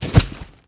anonGardenFall.ogg